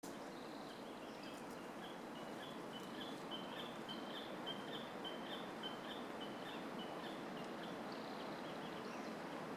Scytalopus magellanicus
Nome em Inglês: Magellanic Tapaculo
Localidade ou área protegida: Parque Nacional Lanín
Condição: Selvagem
Certeza: Gravado Vocal